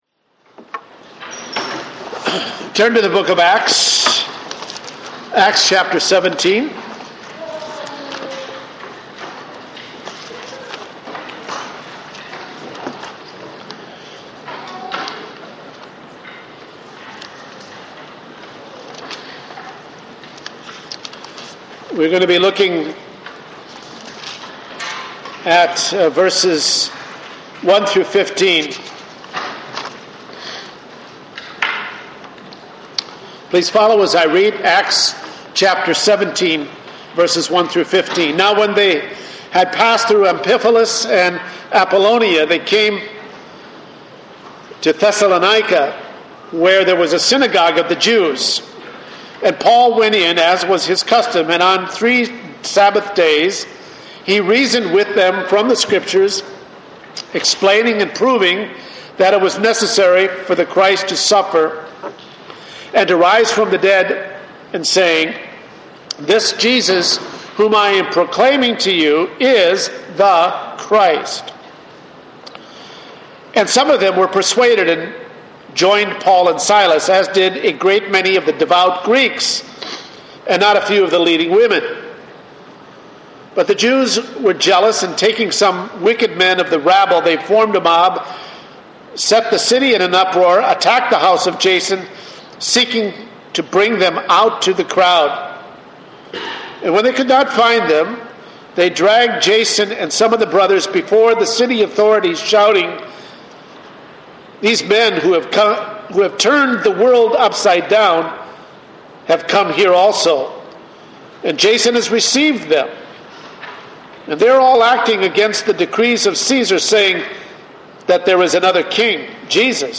Acts Passage: Acts 17:1-15 Service Type: Sunday Morning %todo_render% « What Must I Do To Be Saved?